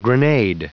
Prononciation du mot grenade en anglais (fichier audio)
Prononciation du mot : grenade